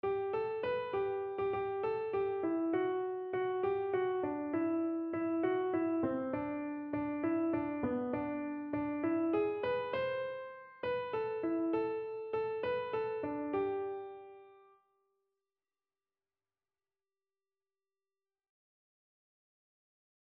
Lyssna till melodin på piano: